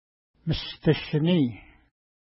Mishtashini Next name Previous name Image Not Available ID: 495 Longitude: -60.4993 Latitude: 53.2487 Pronunciation: mistəʃəni: Translation: Big Rock Feature: mountain Explanation: It is a big rocky mountain.